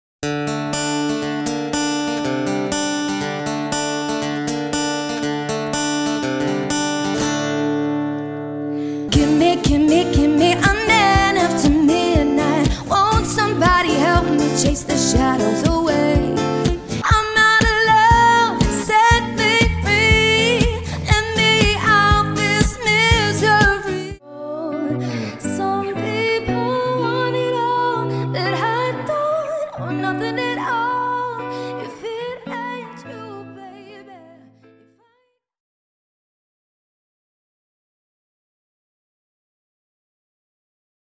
Acoustic Duo Mix